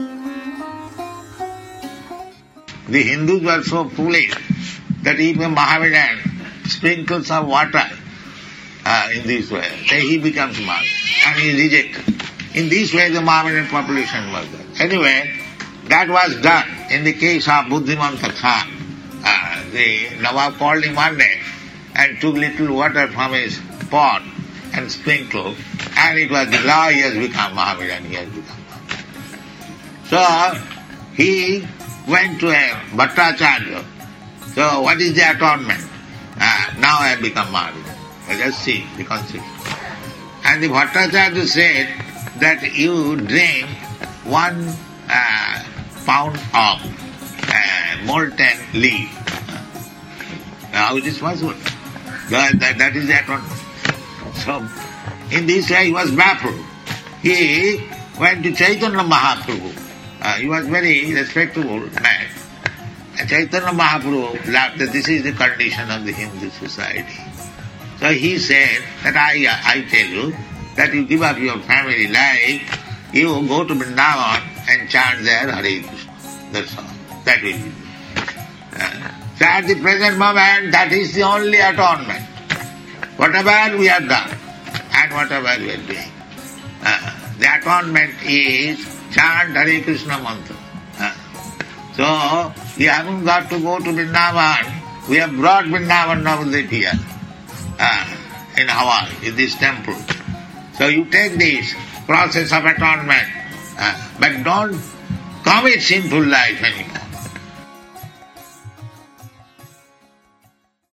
(750615 – Lecture SB 06.01.07 – Honolulu)